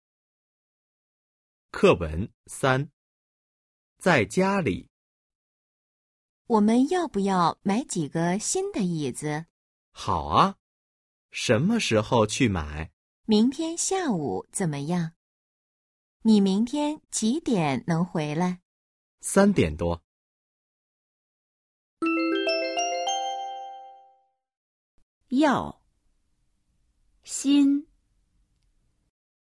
Bài hội thoại 3: 🔊 在家里 – Ở nhà  💿 01-03